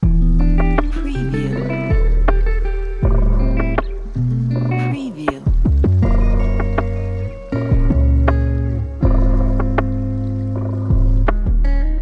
مجموعه سمپل های هیپ هاپ | دانلود 1500 سمپل هیپ هاپ
demo-hiphop.mp3